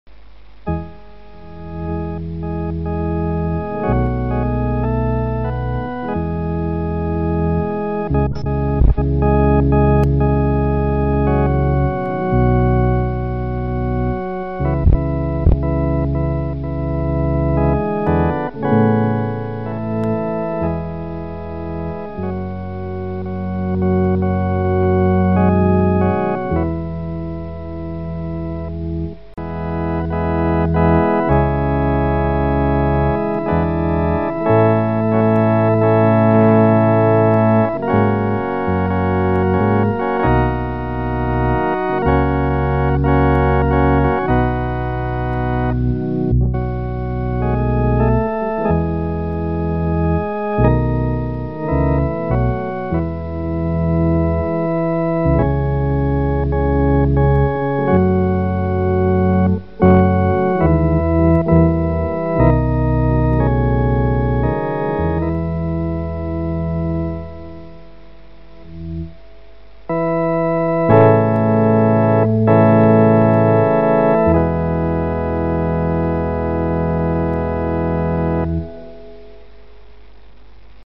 Masonic Music for Lodge and Chapter
Organ.